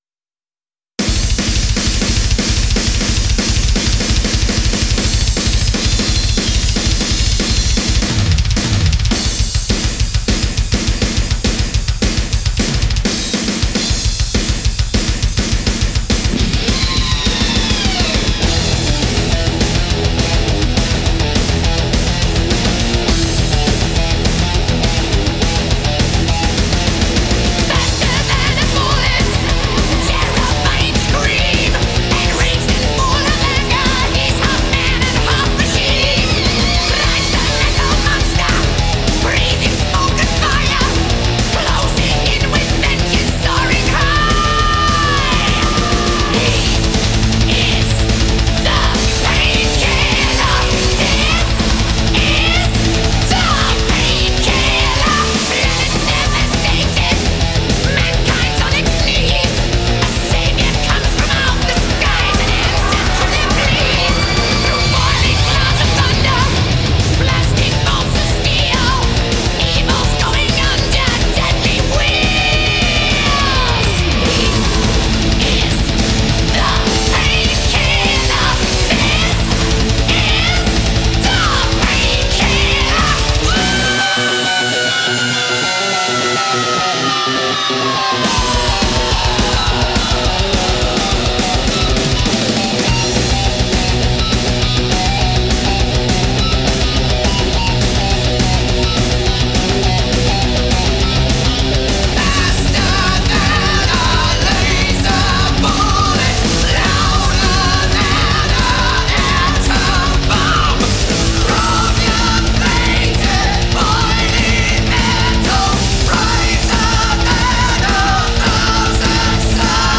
Жанр: хэви-метал, спид-метал, хард-рок